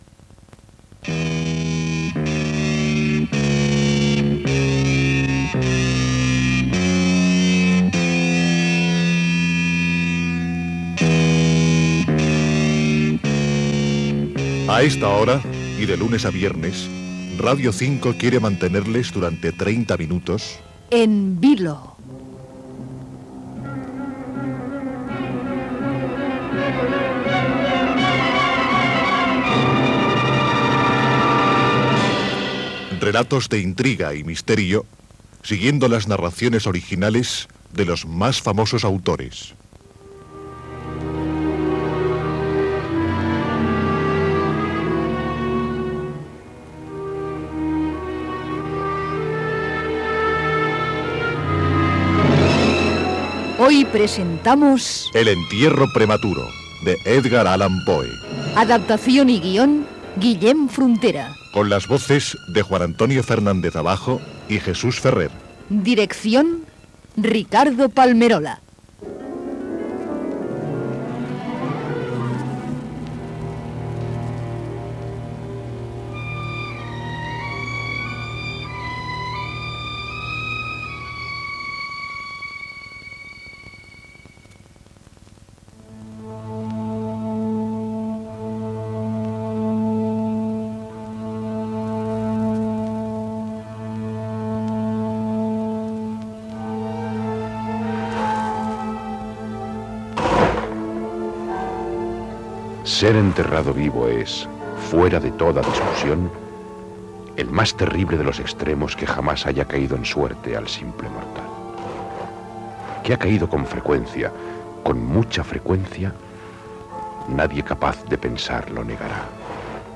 Careta del programa amb els crèdits
Adaptació de l'obra "El entierro primitivo" d'Edgar Allan Poe. Gènere radiofònic Ficció